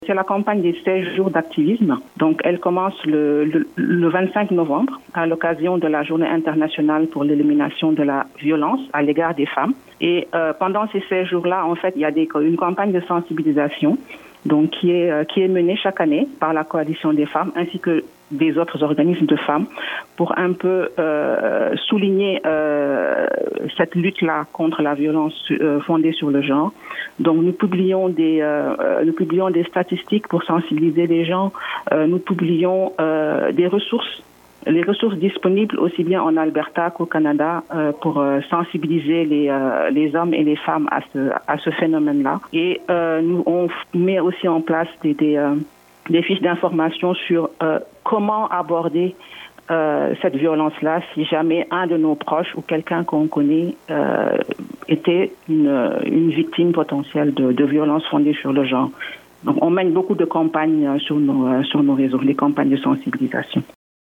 Dans le cadre de la Journée nationale de commémoration et d’action contre la violence faite aux femmes, le Campus Saint-Jean et la Coalition des Femmes de l’Alberta ont ténus une Cérémonie d’Hommage communautaire aux Victimes du Féminicide du 6 décembre 1989, qui s’est déroulé le 6 décembre 2022 au Pavillon McMahon du Campus Saint-Jean de l’Université de l’Alberta.